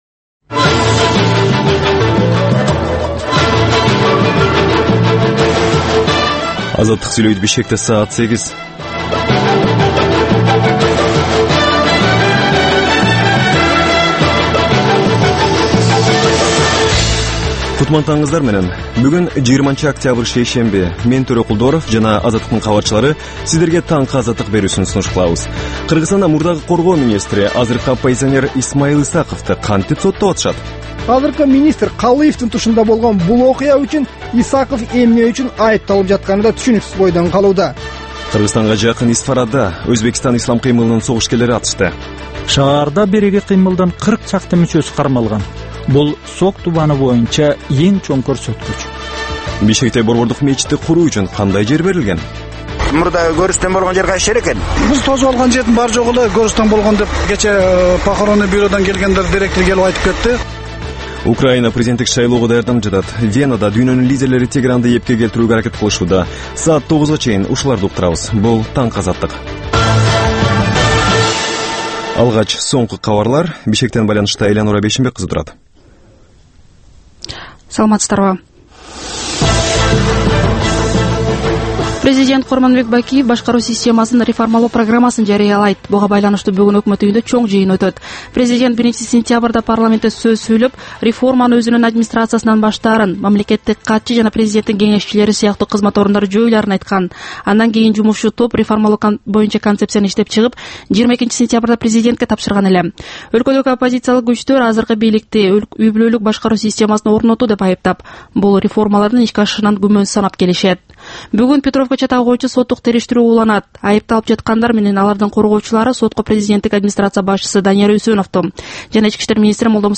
Бул таңкы үналгы берүү жергиликтүү жана эл аралык кабарлардан, ар кыл окуялар тууралуу репортаж, маек, тегерек үстөл баарлашуусу, талкуу, баян жана башка берүүлөрдөн турат. "Азаттык үналгысынын" бул таңкы берүүсү Бишкек убактысы боюнча саат 08:00ден 09:00га чейин обого чыгарылат.